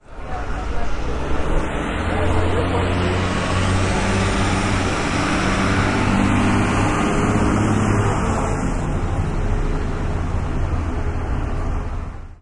关闭警报器的应急车辆
描述：紧急车辆正是如此。 警笛响起，深夜驶过。再次以24bit/44khz录制，但以wav文件形式留下。两次录音都是立体声。
Tag: 环境 车辆 人群